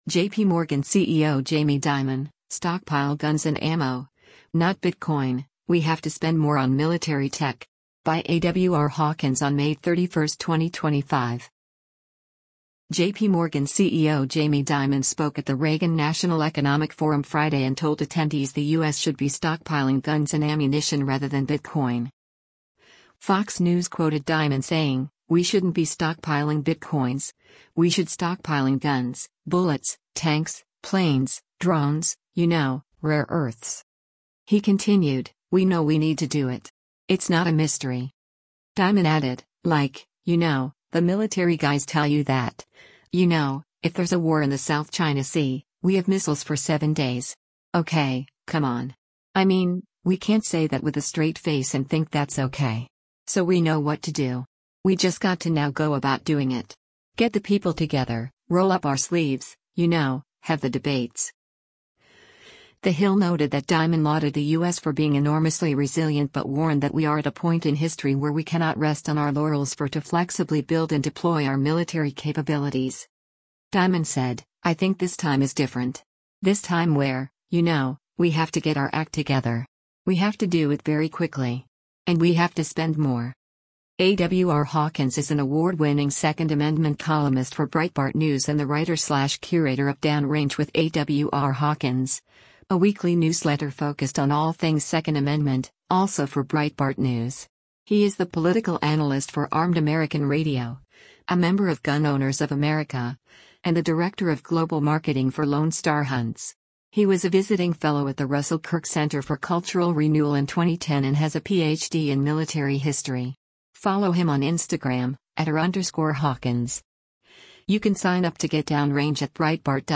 JPMorgan CEO Jamie Dimon spoke at the Reagan National Economic Forum Friday and told attendees the U.S. should be stockpiling guns and ammunition rather than bitcoin.